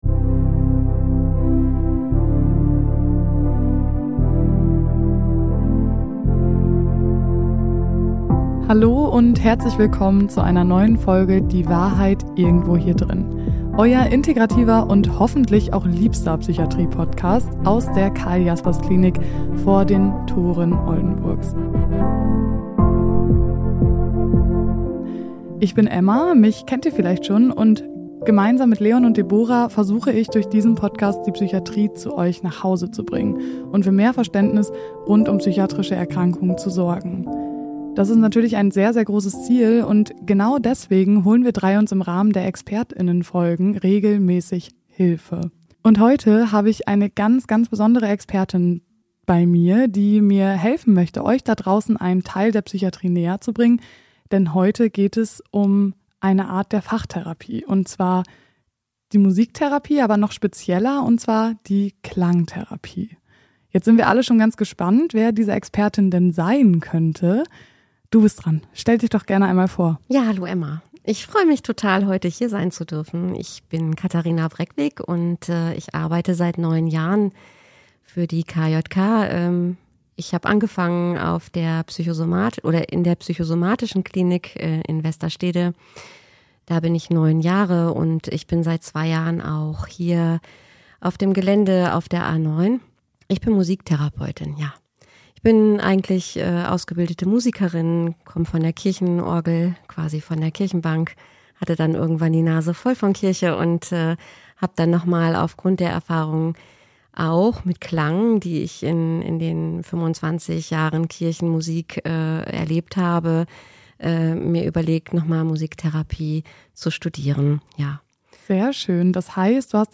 #43 KLANGTHERAPIE Experten-Talk ~ Die Wahrheit Irgendwo Hier Drinnen Podcast